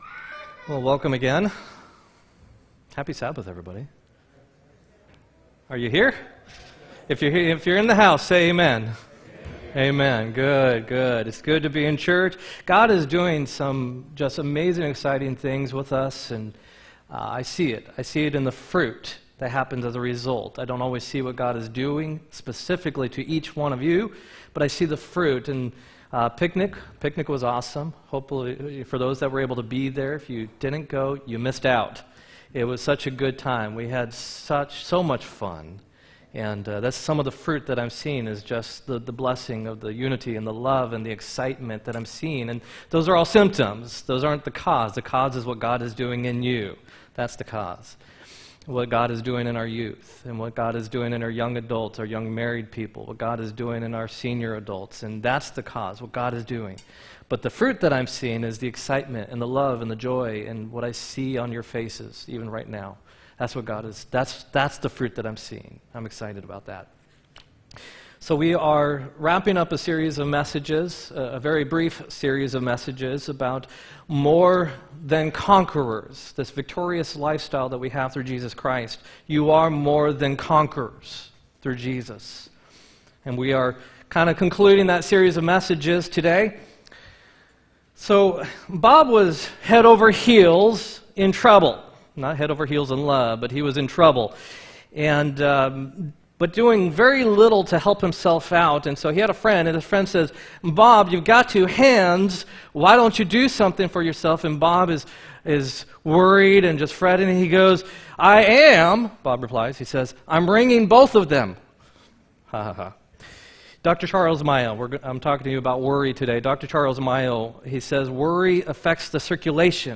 4-28-18 sermon
4-28-18-sermon.m4a